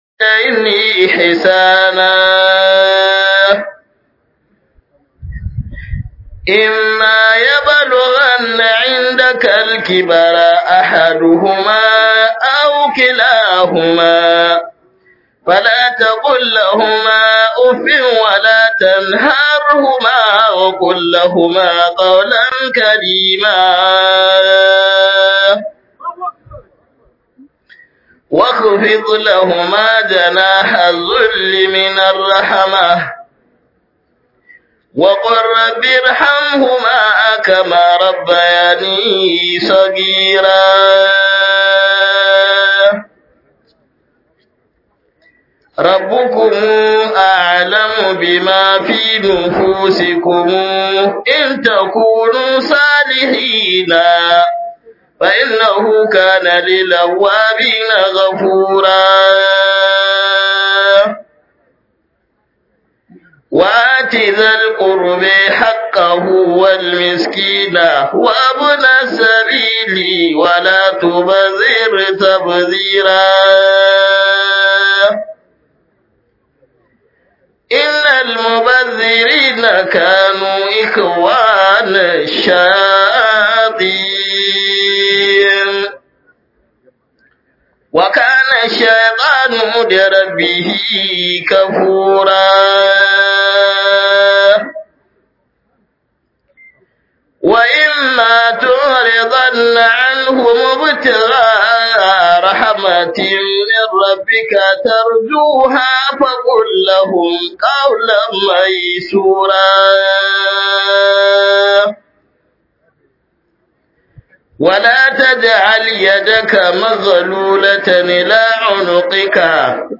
Tambayoyi Zuwa Ga ’Yan Qala-Qato 91 Zuwa 100 a Lagos - MUHADARA by Sheikh (Dr) Kabir Haruna Gombe